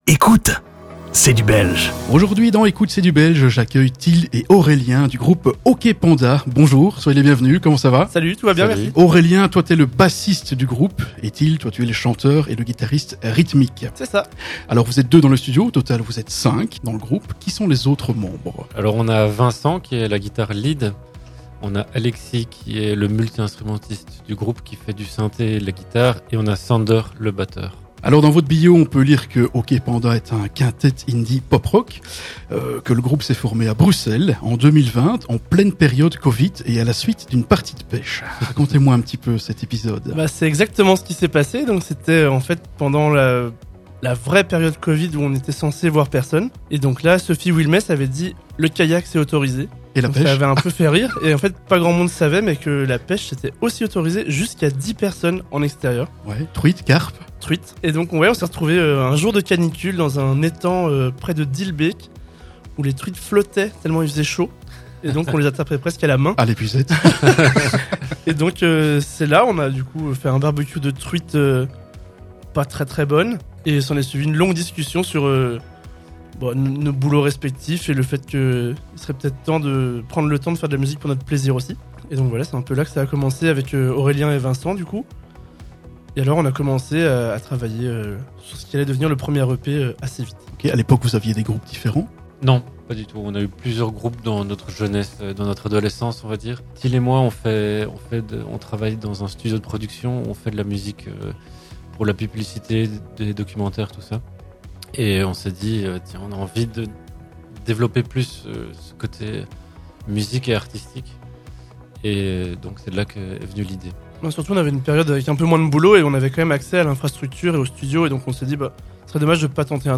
Dans ce podcast, découvrez l'interview du groupe OK PANDA